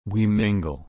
/ˈmɪŋ.ɡəl/